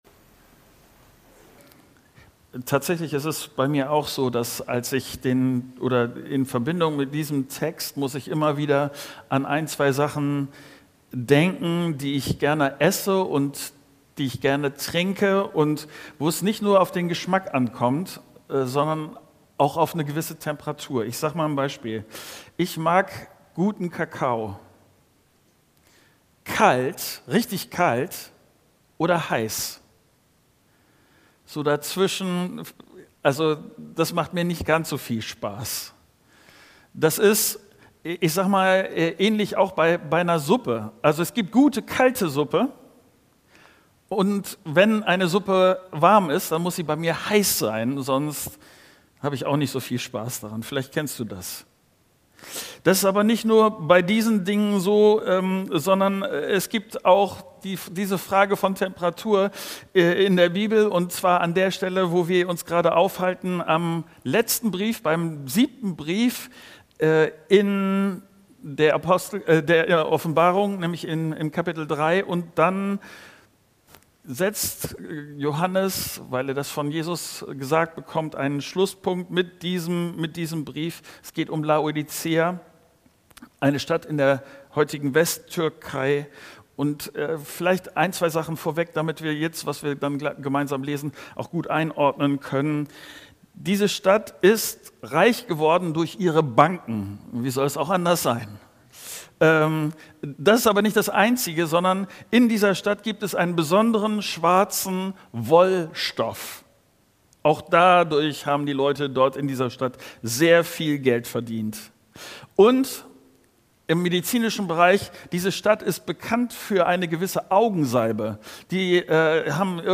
Dateien zum Herunterladen Update Predigt als MP4